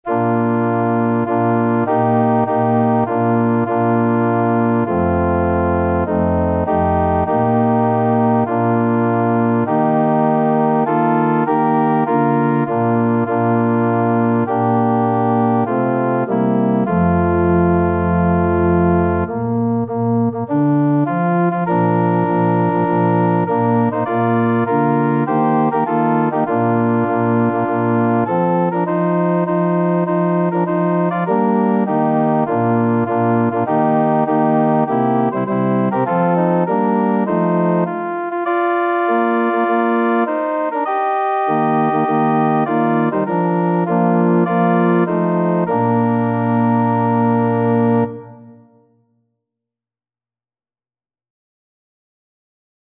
4/4 (View more 4/4 Music)
Organ  (View more Intermediate Organ Music)
Classical (View more Classical Organ Music)